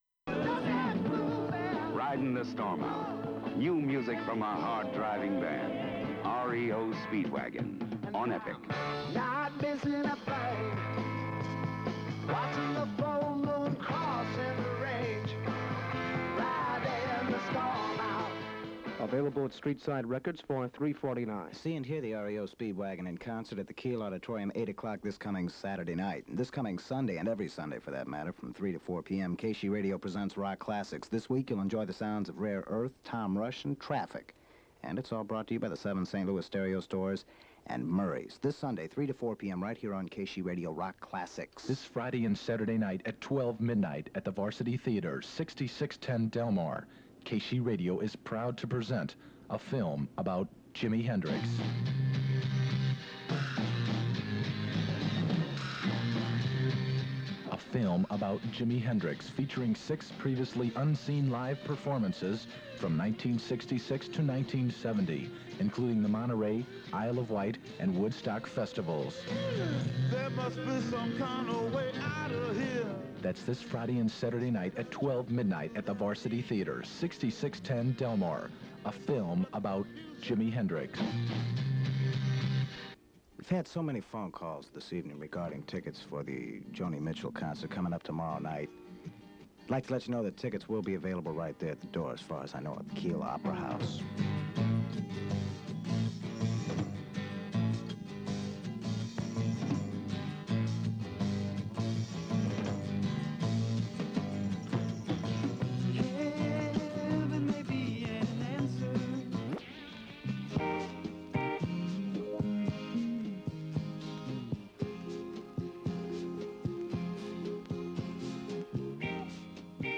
KSHE Unknown announcer aircheck · St. Louis Media History Archive
Original Format aircheck